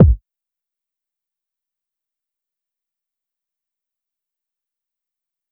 Kick (Get It Together).wav